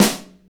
SNR MTWN 07R.wav